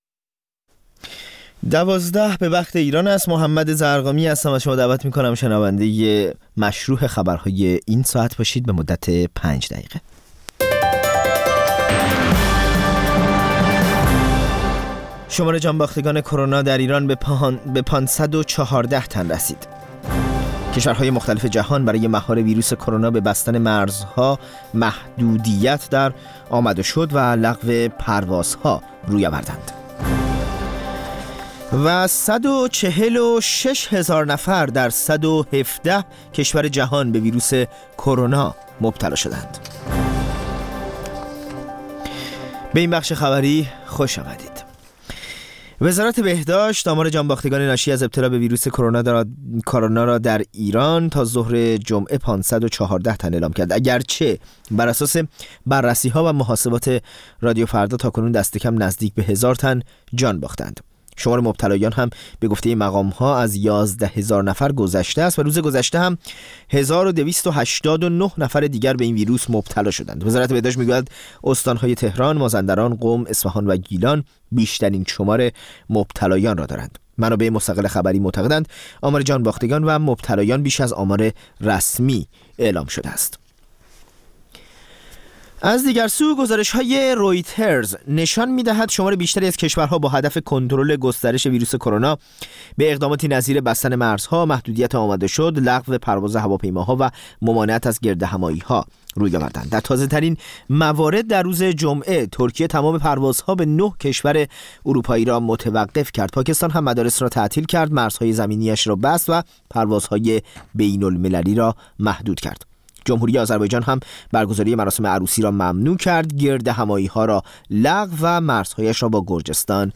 اخبار رادیو فردا، ساعت ۱۲:۰۰